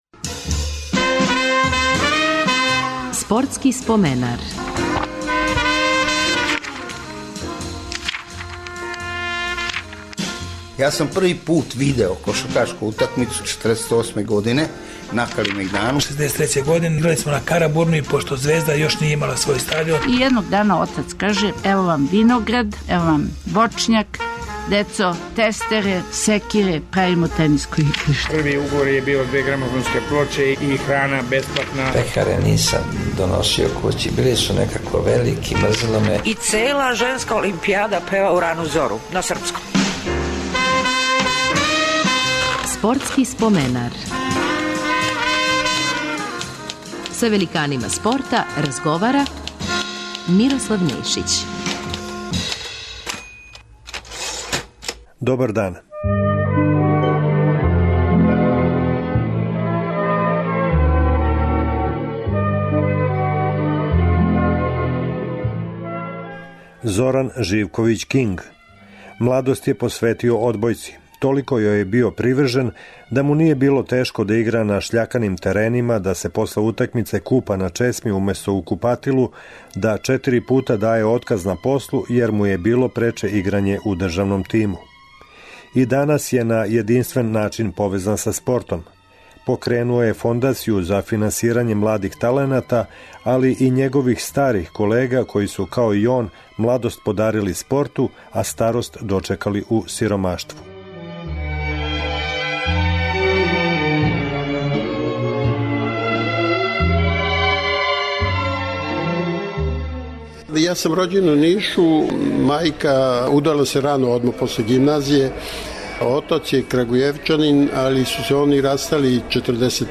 Радио Београд 1 од 16 до 17 часова.